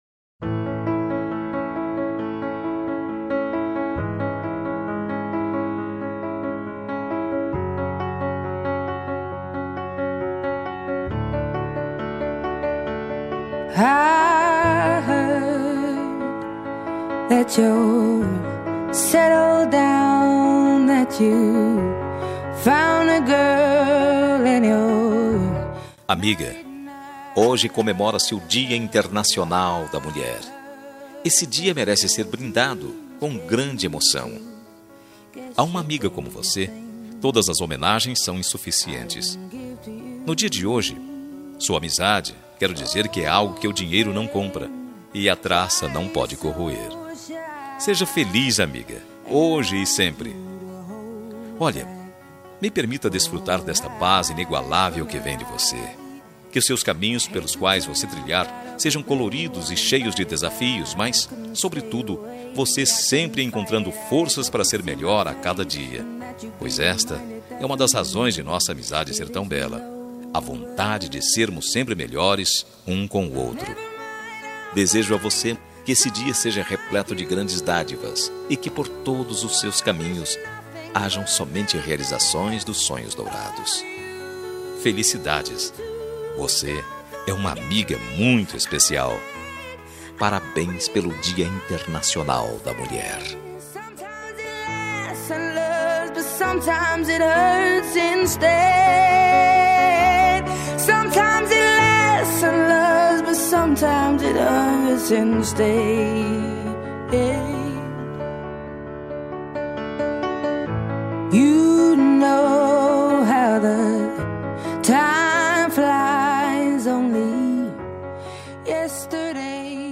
Dia das Mulheres Para Amiga – Voz Masculina – Cód: 5359